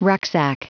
Prononciation du mot rucksack en anglais (fichier audio)
Prononciation du mot : rucksack